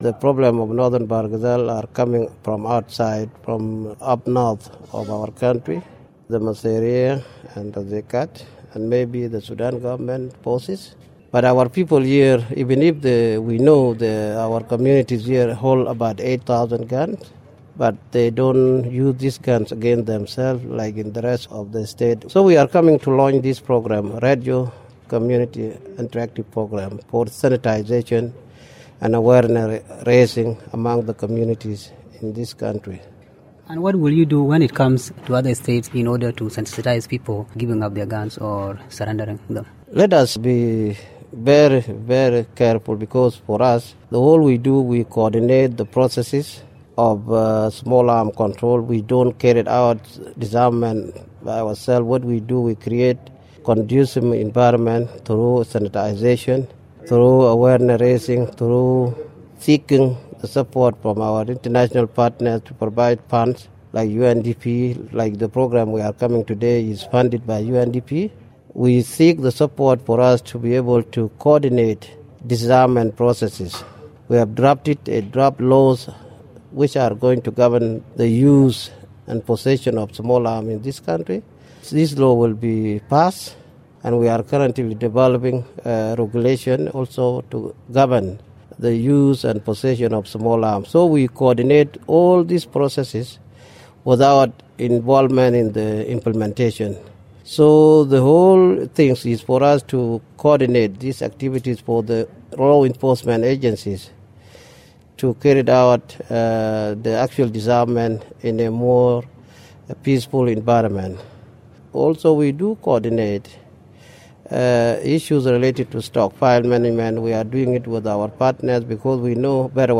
Interview with Riak Gok Majok